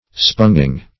Sponging - definition of Sponging - synonyms, pronunciation, spelling from Free Dictionary
Sponging \Spon"ging\ (-j[i^]ng),